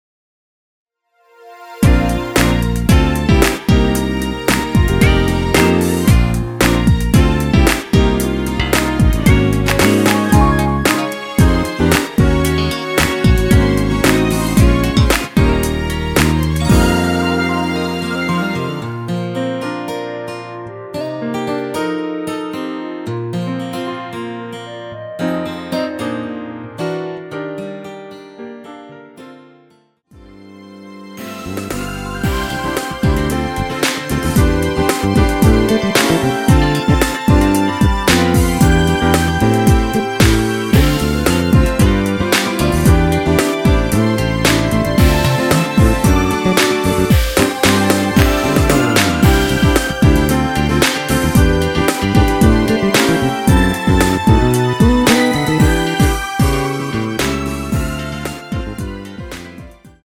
원키에서(+2)올린 멜로디 포함된 MR 입니다.(미리듣기 참조)
Bb
앞부분30초, 뒷부분30초씩 편집해서 올려 드리고 있습니다.
중간에 음이 끈어지고 다시 나오는 이유는
(멜로디 MR)은 가이드 멜로디가 포함된 MR 입니다.